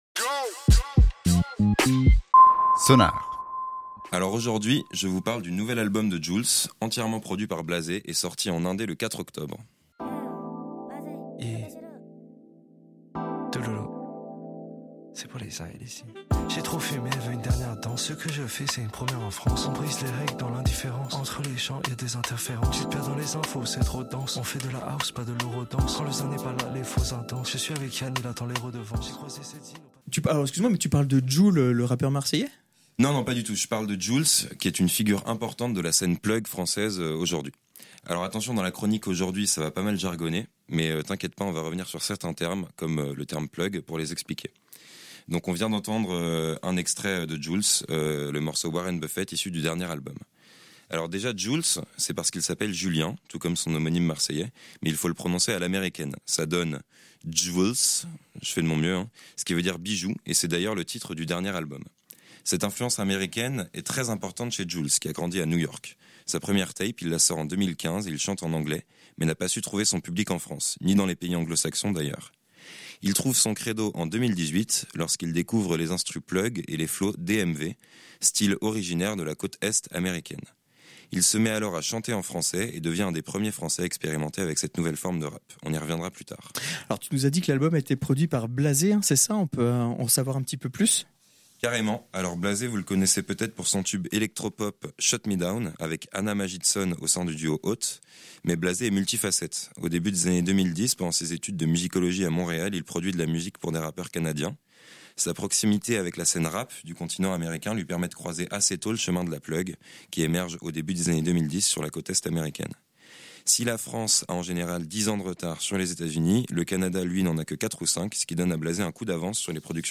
Le tout sur des prods inspirées de la plugg, avec ses synthés aériens et ses ambiances douces.